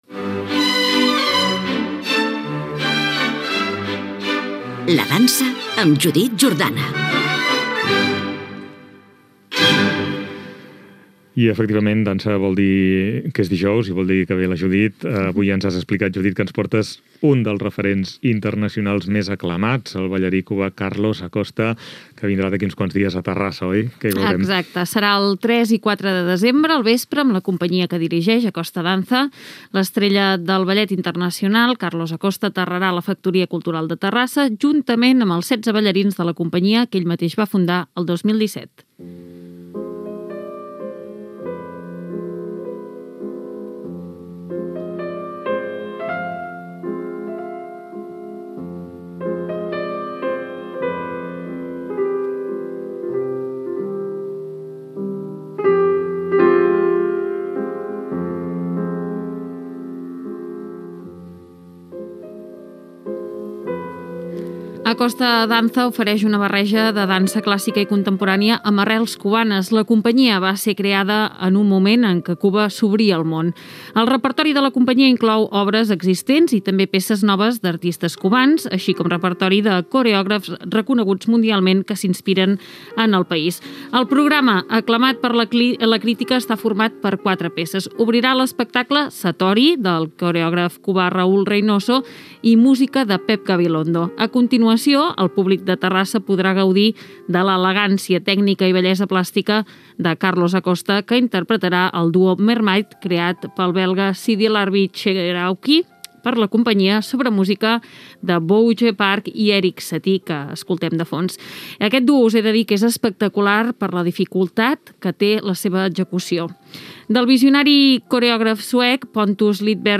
Entrevista a Carlos Acosta
FM